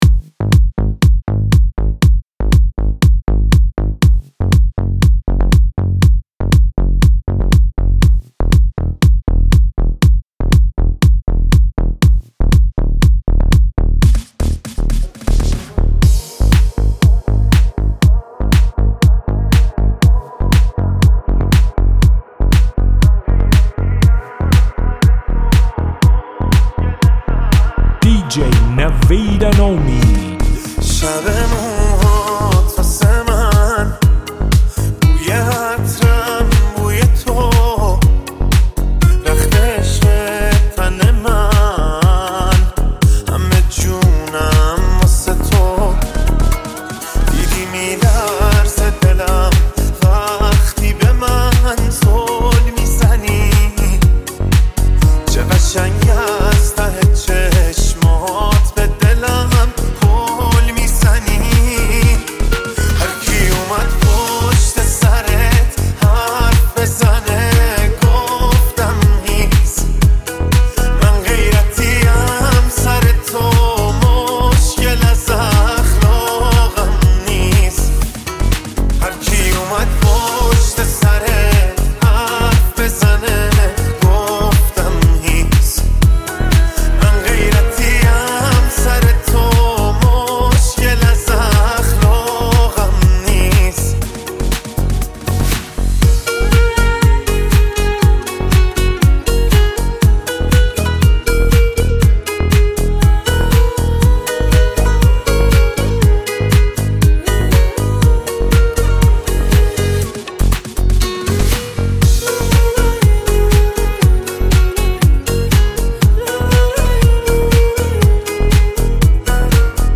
آهنگ ریمیکس